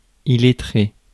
Ääntäminen
France: IPA: [i.let.ʁe]